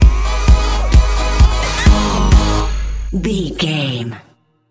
Aeolian/Minor
A♭
Fast
drum machine
synthesiser